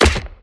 枪声 01 " gunsound20
描述：快速制作，未经抛光的低保真枪声。主要用于游戏音频。用foley录制，一点点合成器，大量过载。